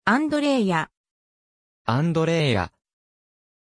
Pronuncia di Andreea
pronunciation-andreea-ja.mp3